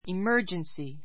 imə́ː r dʒənsi イ マ ～ヂェンスィ